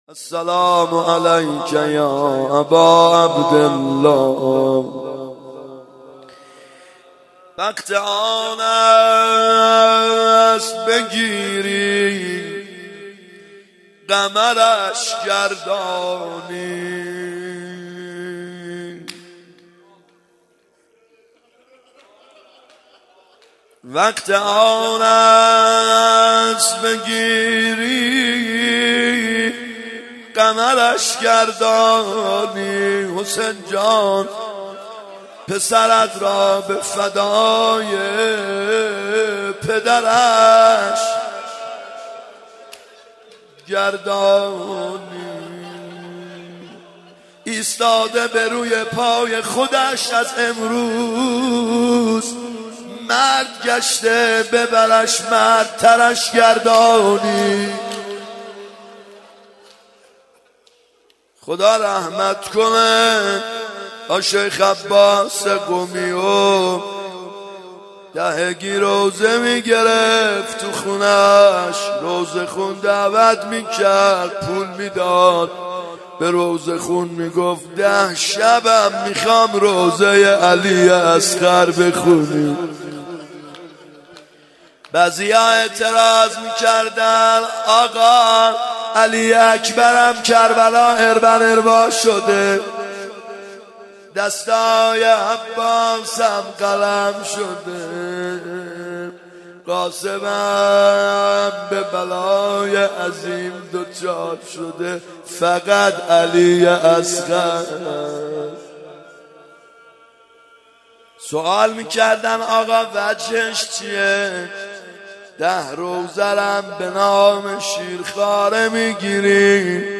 محرم 92 ( هیأت یامهدی عج)